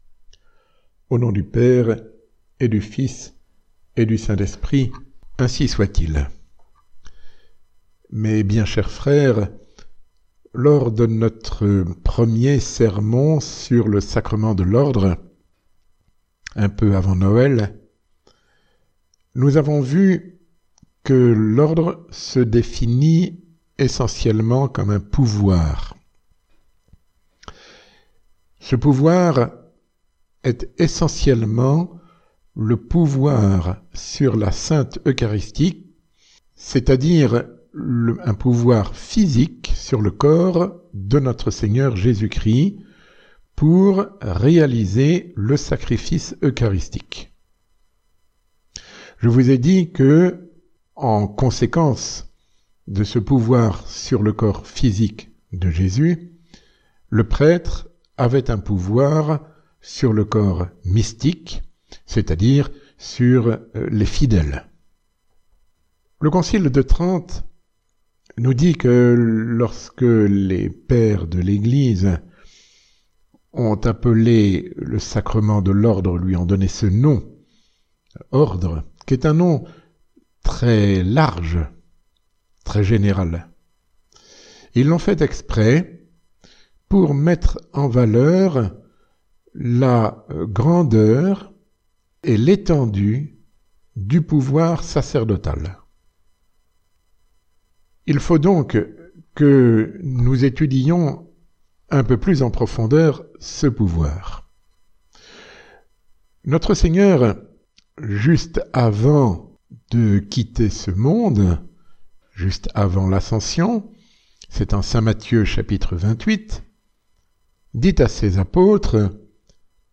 Résumé du sermon